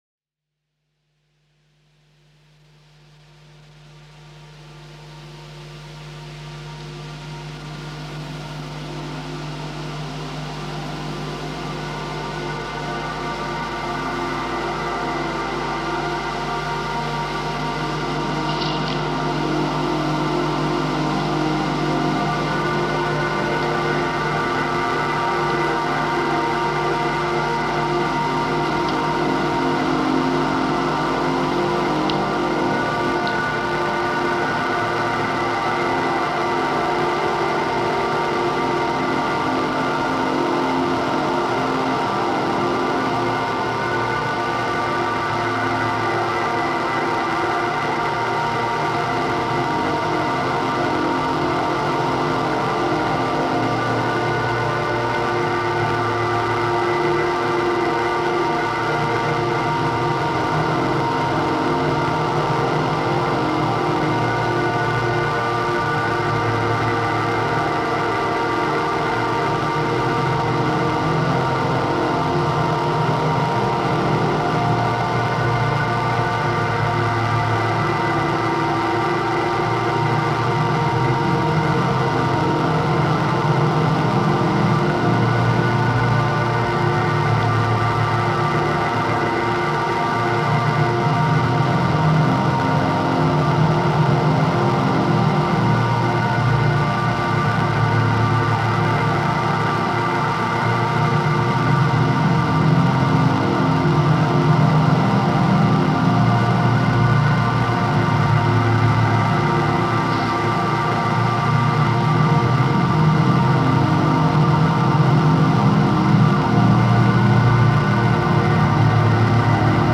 Genre: Ambient/Drone.